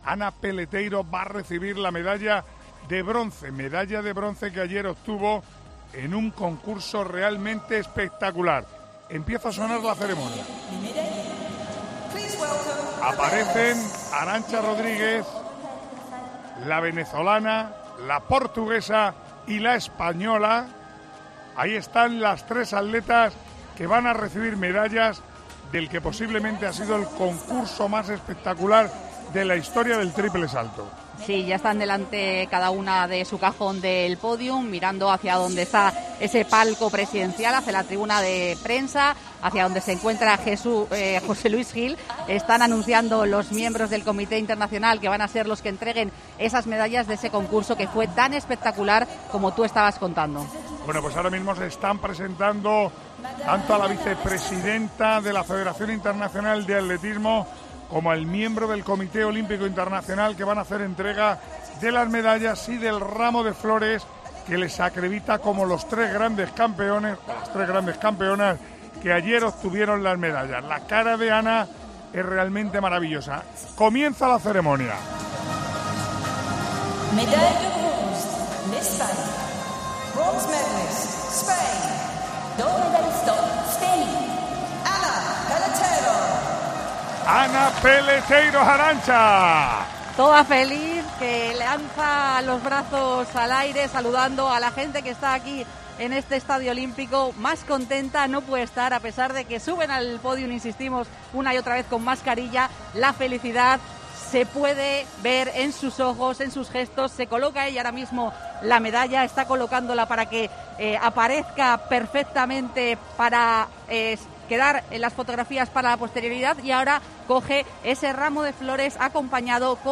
En COPE hemos vivido la ceremonia de medallas del histórico concurso de triple salto donde Ana Peleteiro consiguió la medalla de bronce.